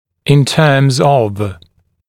[ɪn tɜːmz ɔv][ин тё:мз ов]исходя из, на основе, в свете